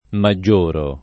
maggiorare v.; maggioro [ ma JJ1 ro ]